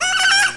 Ayaaaah Sound Effect
ayaaaah-1.mp3